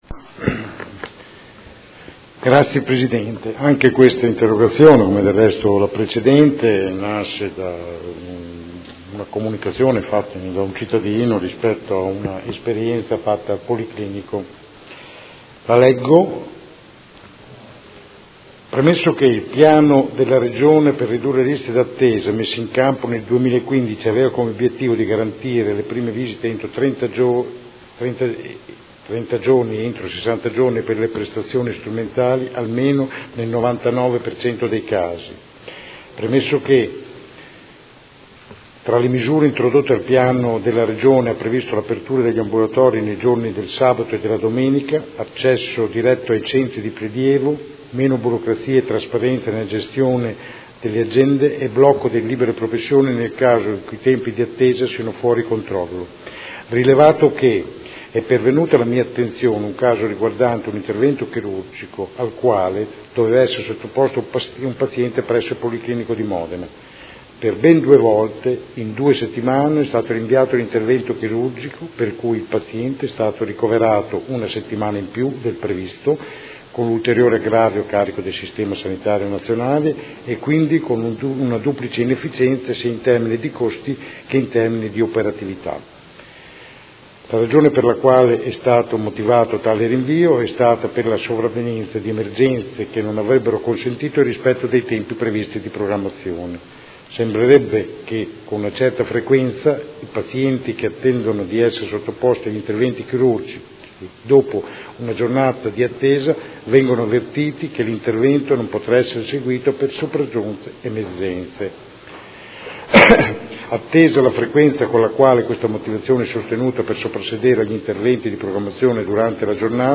Seduta del 21/07/2016 Interrogazione del Consigliere Rocco (FAS-SI) avente per oggetto: Liste di attesa in materia sanitaria. Riferimento: attuazione Piano Regionale per ridurre le liste di attesa